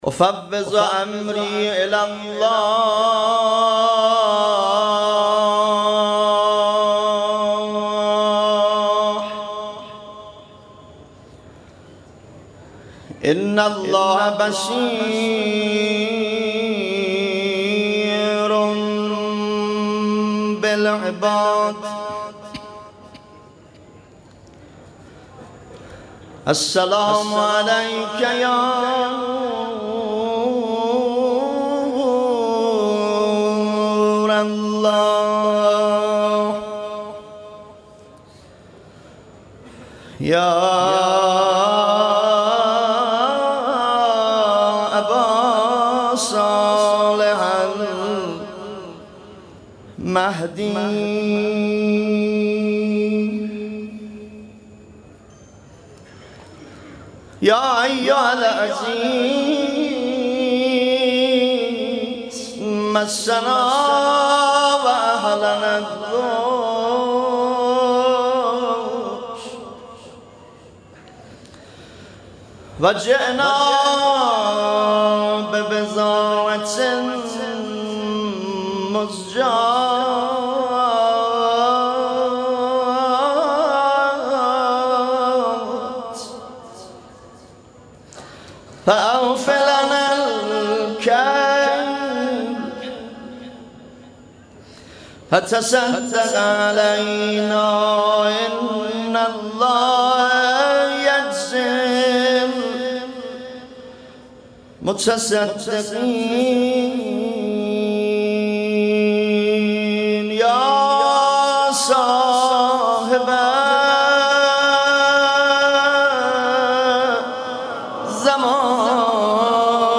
مداحی آذری نوحه ترکی
طشت گذاری